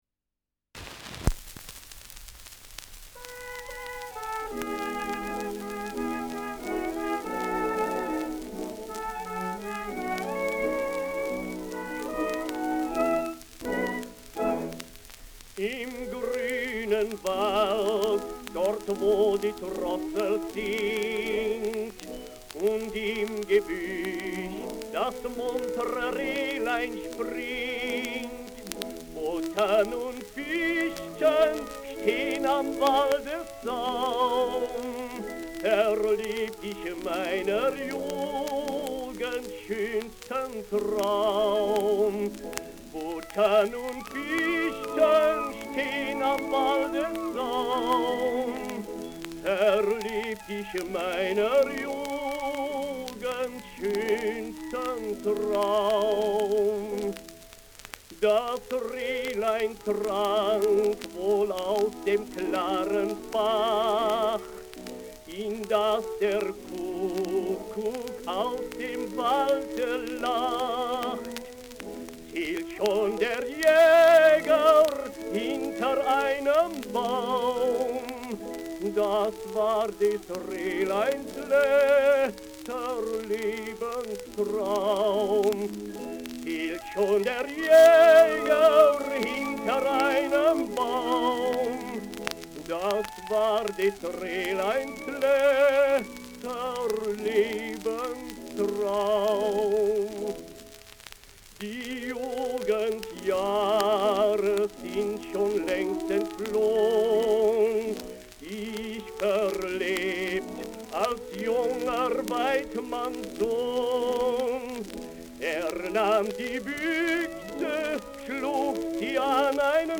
Im grünen Wald, dort wo die Drossel singt : Volkslied
Schellackplatte
Stärkeres Grundrauschen : Gelegentlich leichtes bis stärkeres Knacken
[unbekanntes Ensemble] (Interpretation)